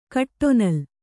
♪ kaṭṭonal